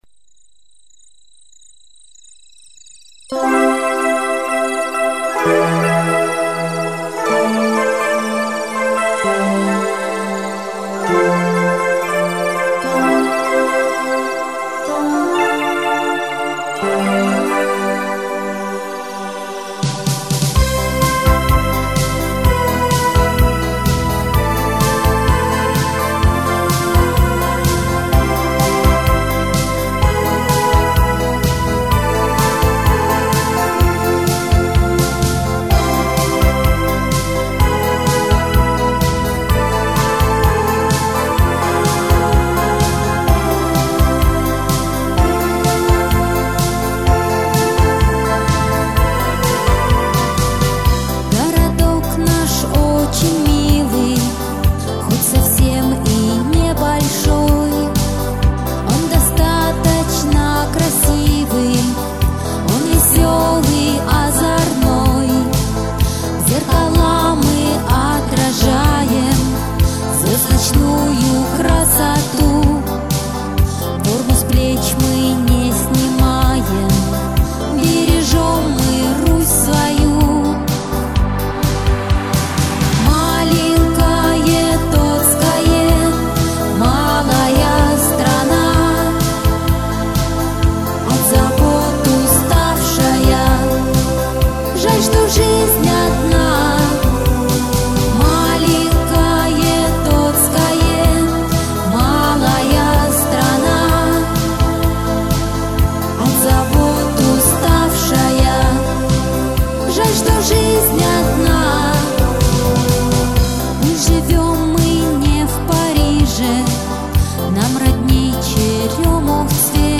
на домашнем компьютере